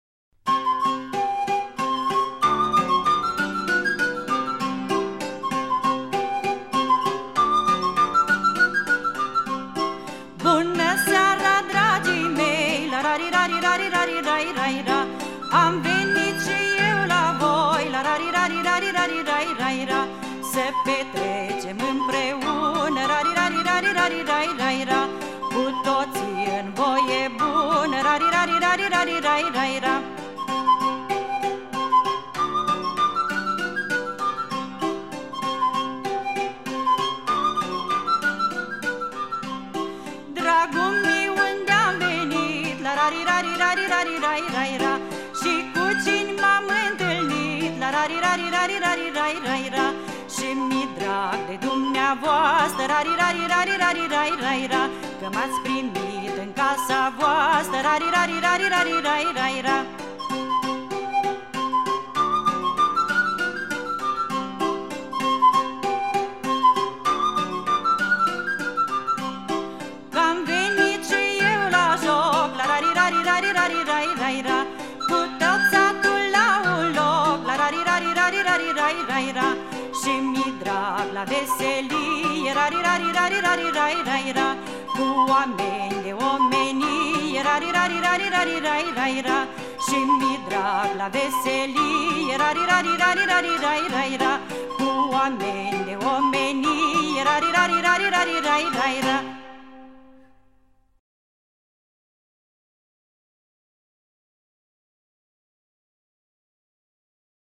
歌聲清脆優美，乾淨的嗓 音更襯托出羅馬尼亞排笛的獨特音色。